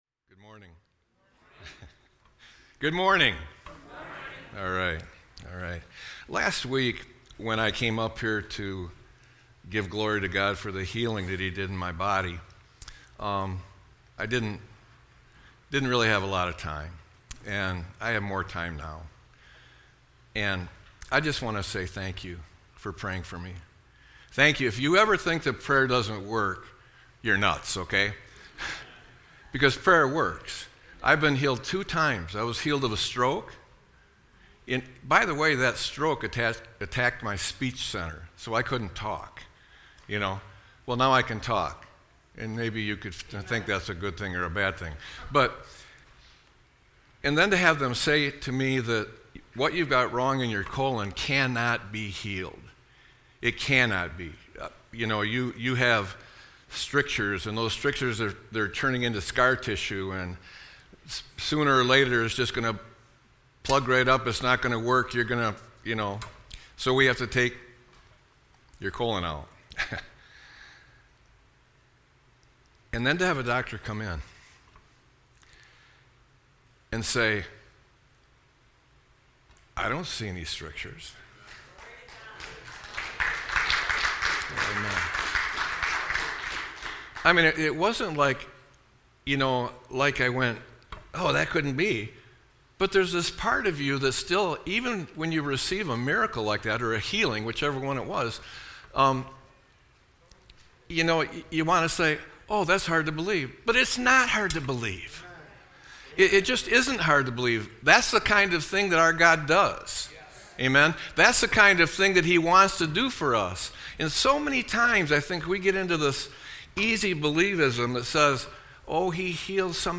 Sermon-8-17-25.mp3